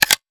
metahunt/weapon_foley_pickup_13.wav at master
weapon_foley_pickup_13.wav